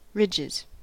Ääntäminen
Ääntäminen US Haettu sana löytyi näillä lähdekielillä: englanti Käännöksiä ei löytynyt valitulle kohdekielelle. Ridges on sanan ridge monikko.